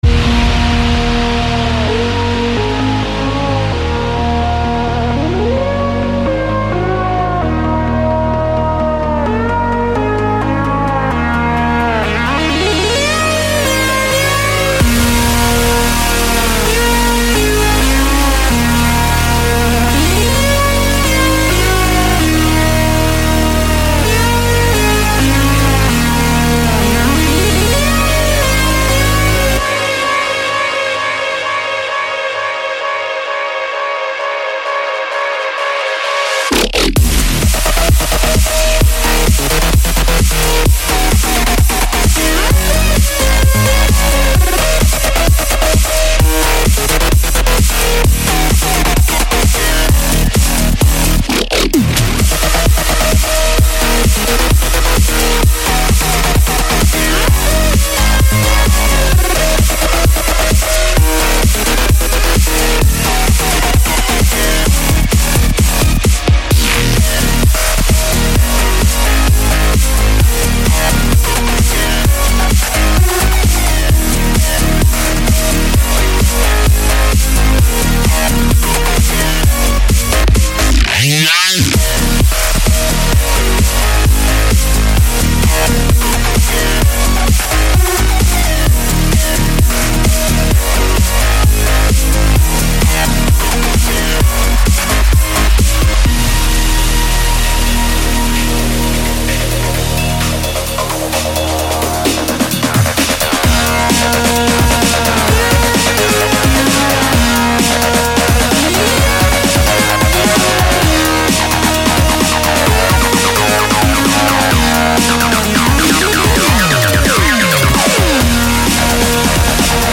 Интересен лид который играет в самом начале.
На сколько я понял, тут вся фишка в питче и вибрато.